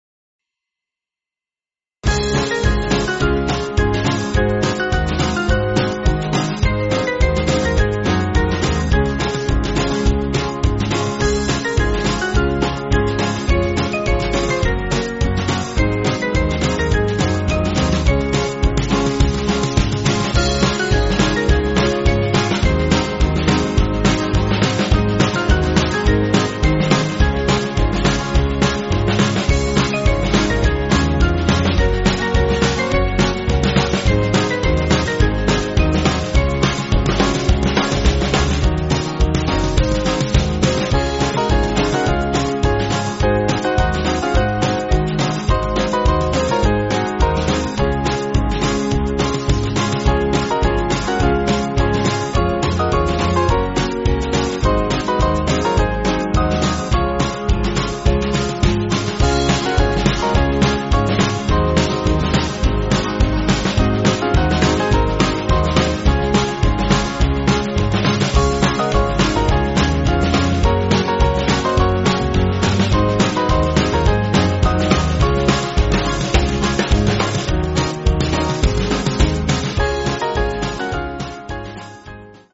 Small Band
(CM)   3/Db